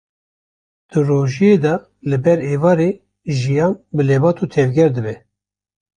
/ʒɪˈjɑːn/